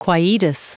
Prononciation du mot quietus en anglais (fichier audio)
Prononciation du mot : quietus